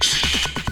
2 Harsh Realm Vox Hush.wav